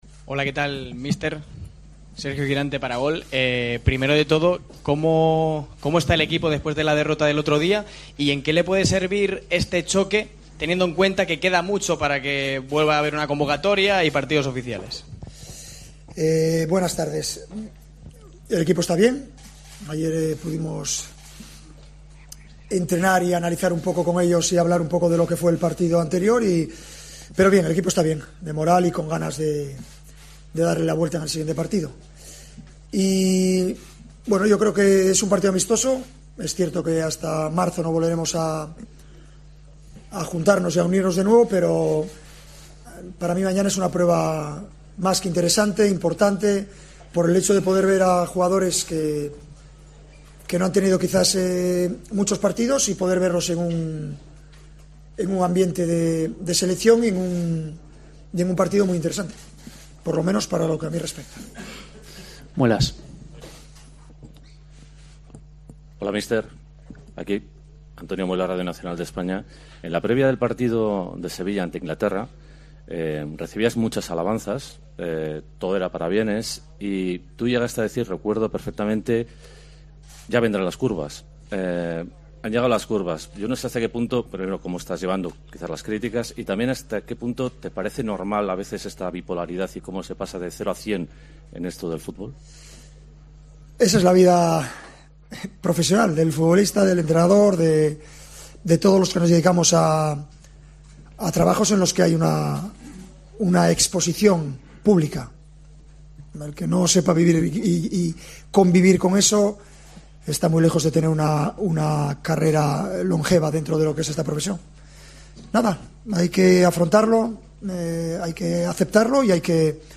Luis Enrique Martínez, seleccionador español, declaró este sábado en Las Palmas, en la víspera del partido amistoso de este domingo ante Bosnia, que, tras la derrota ante Croacia, "De Gea ha sido señalado" y que "es injusto".